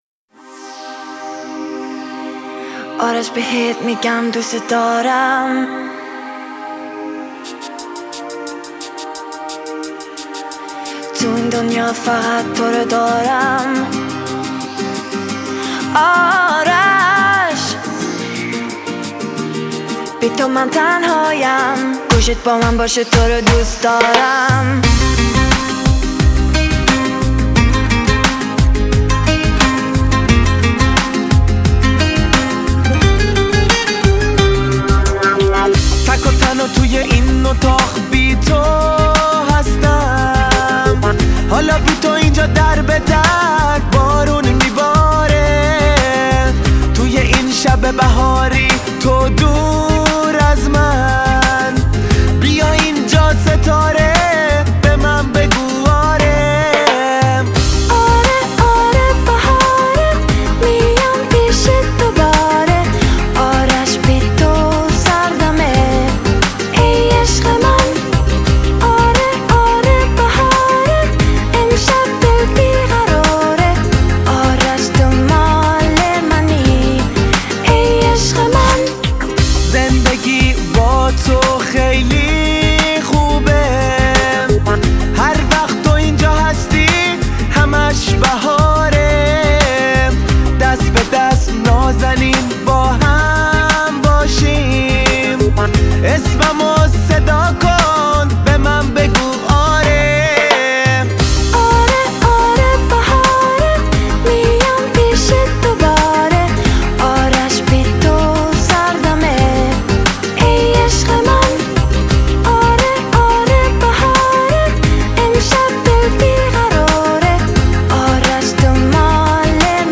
это зажигательный трек в жанре поп и ориентальной музыки
Звучание песни яркое и мелодичное